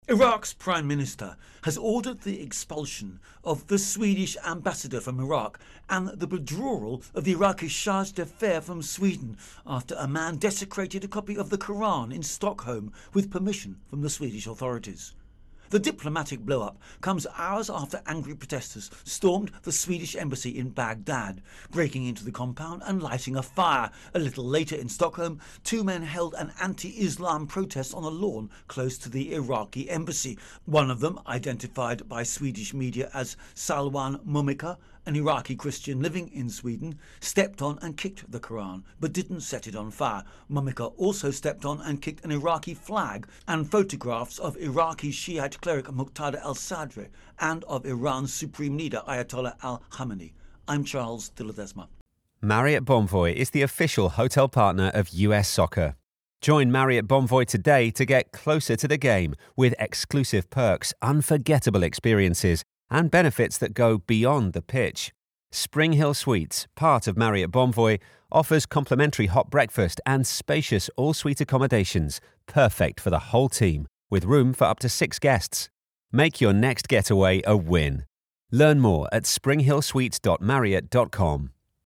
reports on Iraq-Sweden 202 update intro voicer